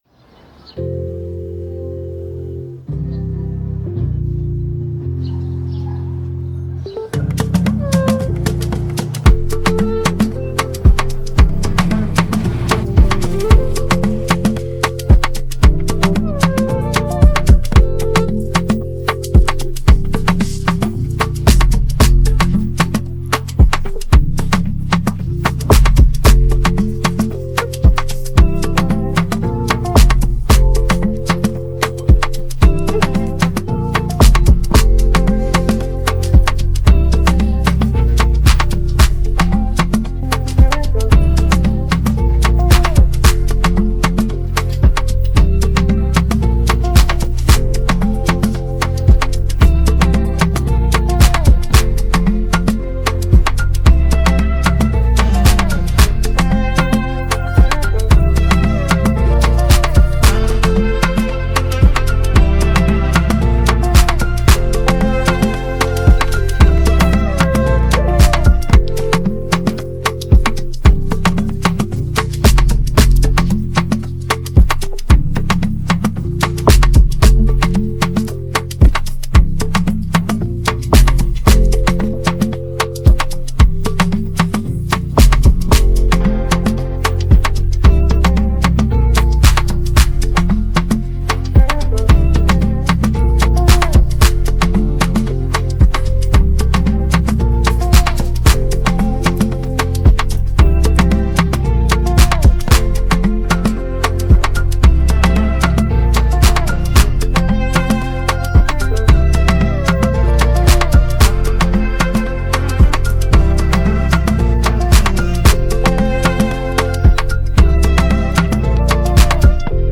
AfrobeatsHighlife